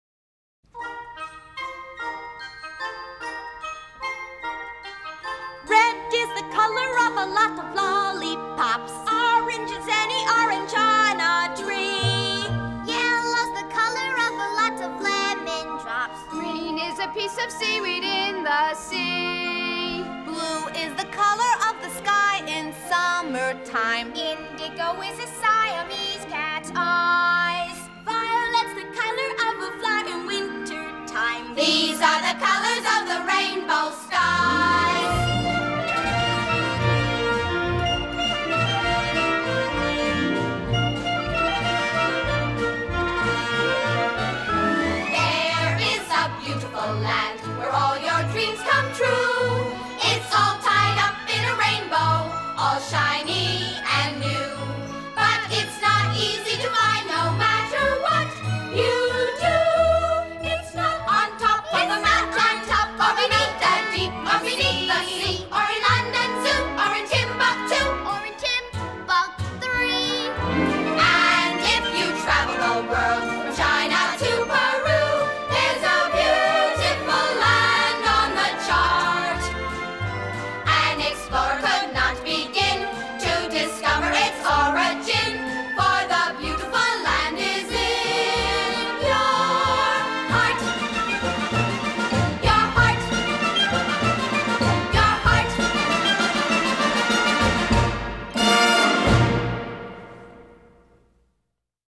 1965   Genre: Musical   Artist